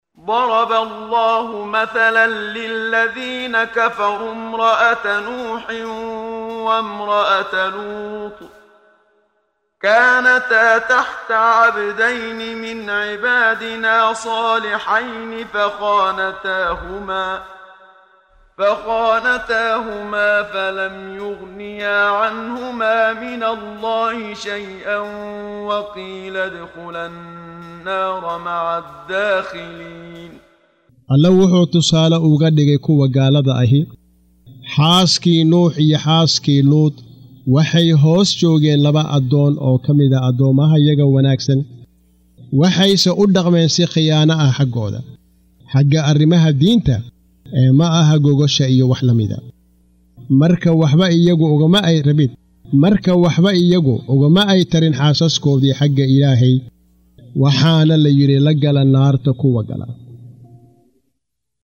Waa Akhrin Codeed Af Soomaali ah ee Macaanida Surah At-Taxriim ( Iska xaaraantimeynta ) oo u kala Qaybsan Aayado ahaan ayna la Socoto Akhrinta Qaariga Sheekh Muxammad Siddiiq Al-Manshaawi.